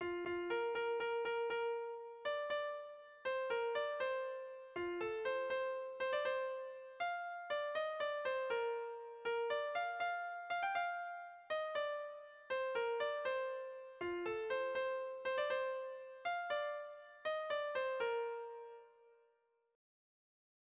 Sentimenduzkoa
Zortziko txikia (hg) / Lau puntuko txikia (ip)
A-B-C-B